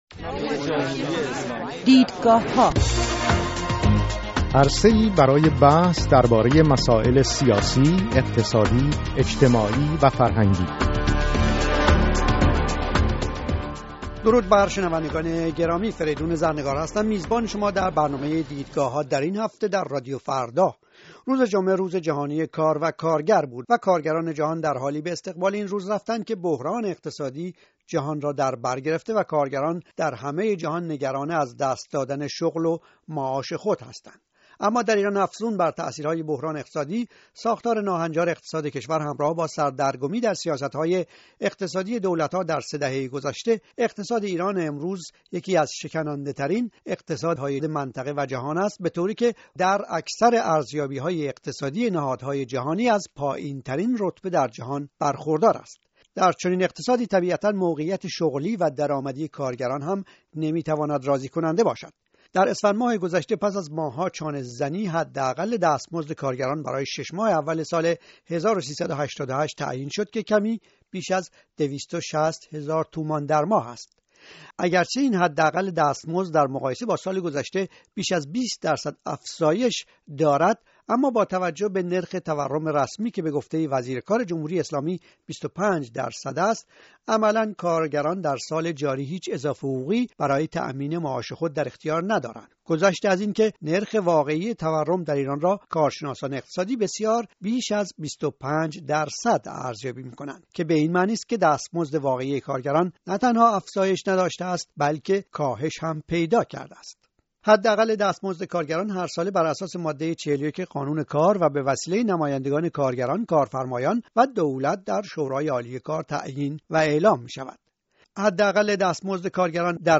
میزگرد بررسی مسائل کارگری ایران به مناسبت روزجهانی کار